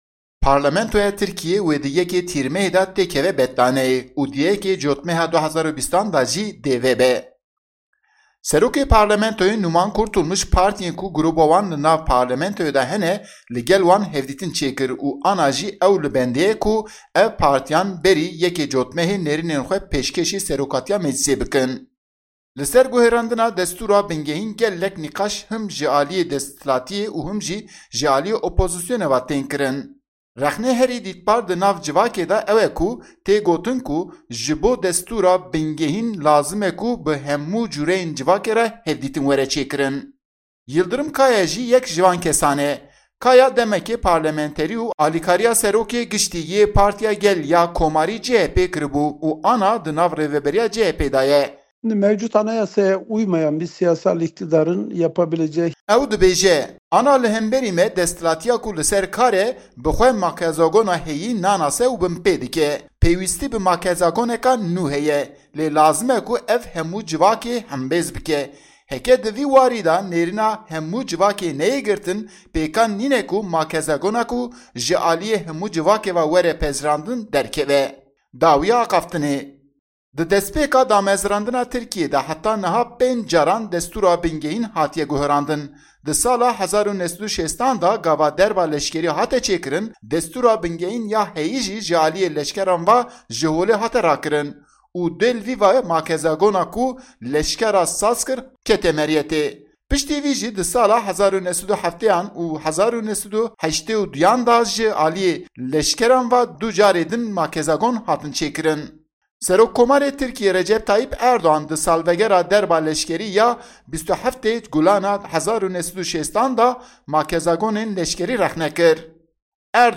Raporta Enqerê - Qanûna Bingehîn ya Nû li Tirkiyê.mp3